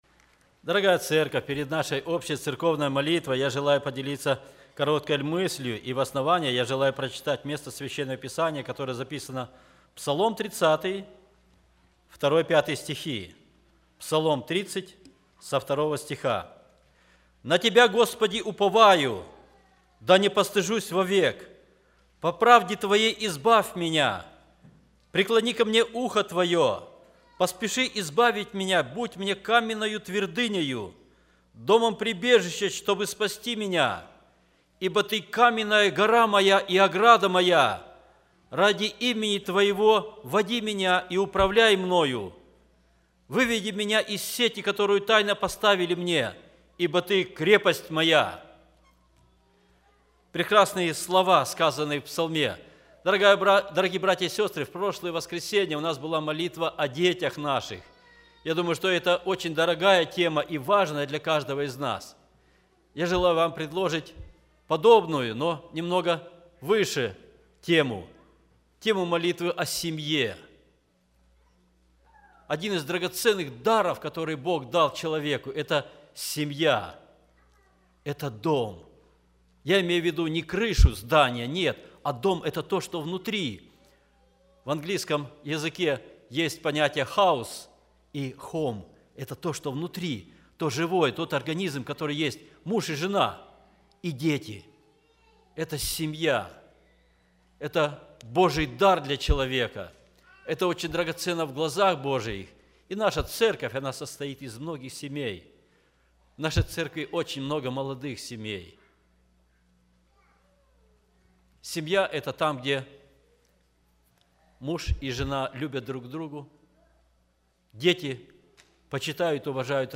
Все Проповеди